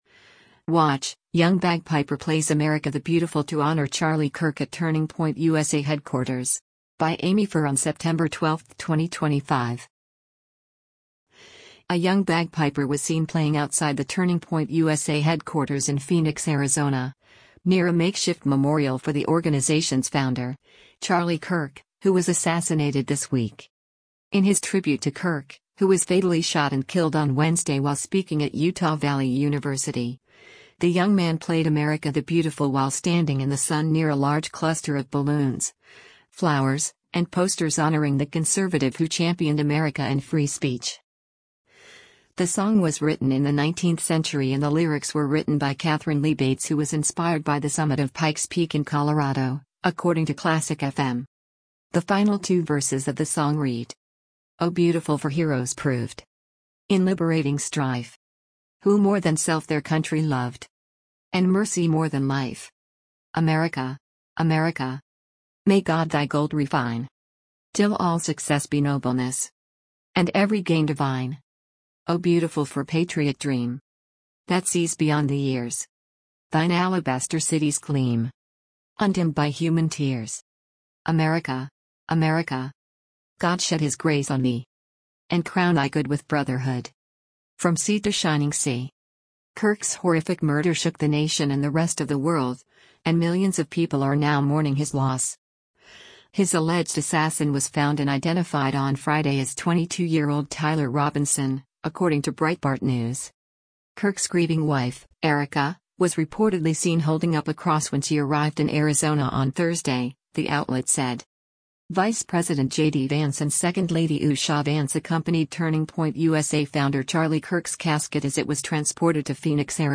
WATCH: Young Bagpiper Plays ‘America the Beautiful’ to Honor Charlie Kirk at Turning Point USA Headquarters
A young bagpiper was seen playing outside the Turning Point USA headquarters in Phoenix, Arizona, near a makeshift memorial for the organization’s founder, Charlie Kirk, who was assassinated this week.
In his tribute to Kirk, who was fatally shot and killed on Wednesday while speaking at Utah Valley University, the young man played “America the Beautiful” while standing in the sun near a large cluster of balloons, flowers, and posters honoring the conservative who championed America and free speech: